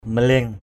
/mə-lie̞ŋ/